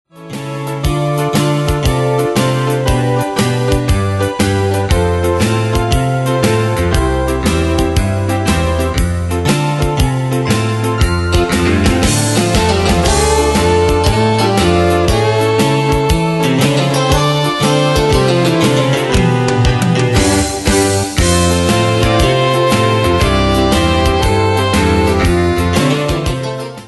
Style: Country Année/Year: 1998 Tempo: 118 Durée/Time: 3.34
Danse/Dance: TwoSteps Cat Id.
Pro Backing Tracks